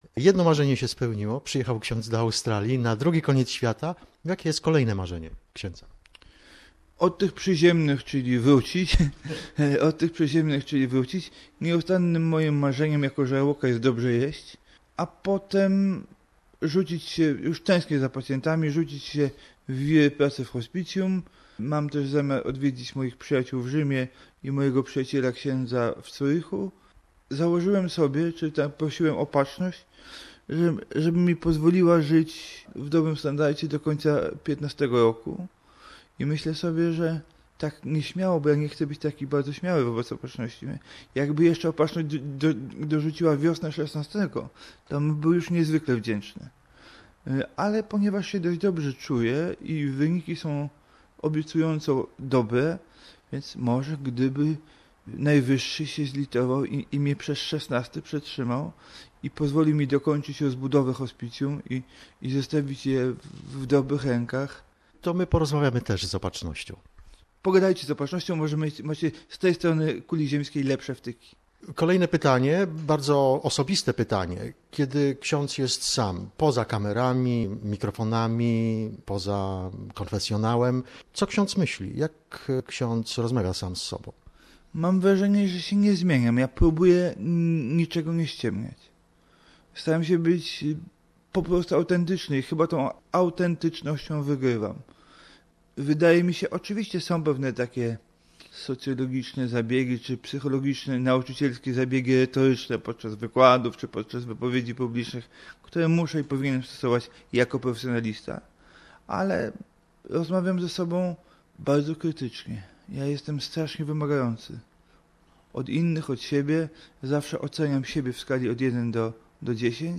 Przypominamy fragment rozmowy z Ks Janem, podczas jego wizyty w Australii w ubiegłym roku.